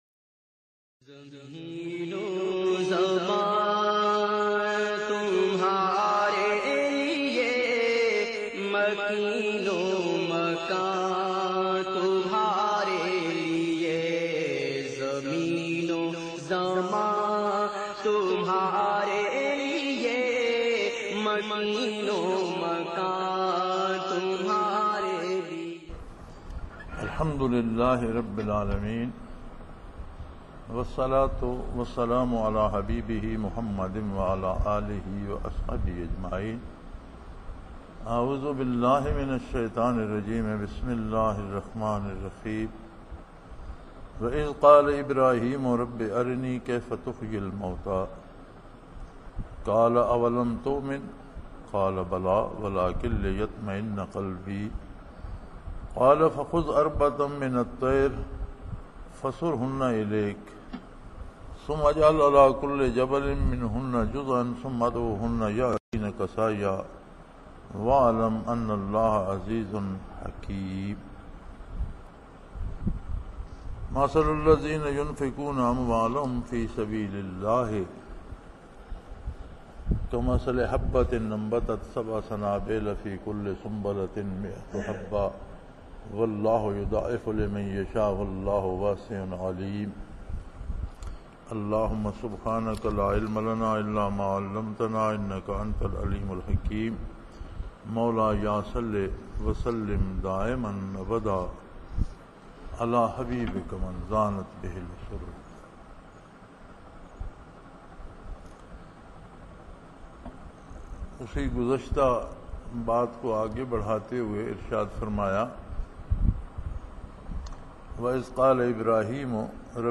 Lectures in Munara, Chakwal, Pakistan on June 30,2006